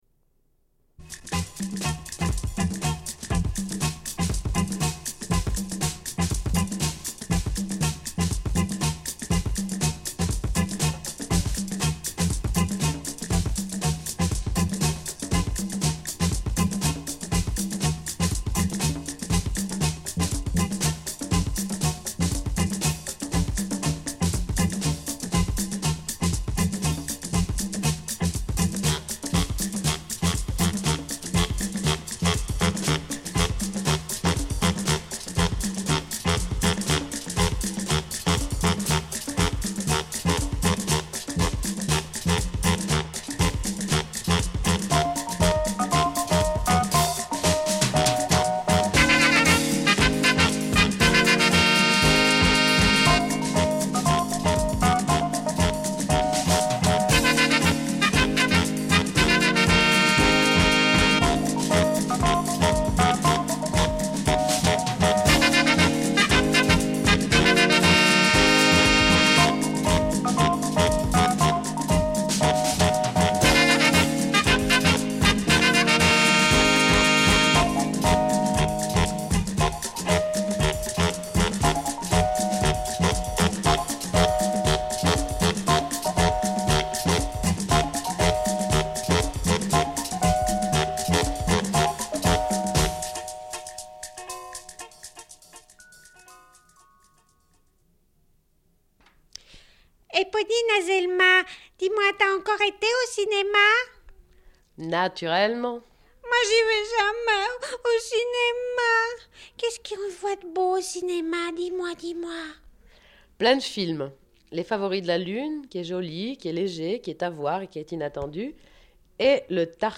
Une cassette audio, face A31:21
Radio Enregistrement sonore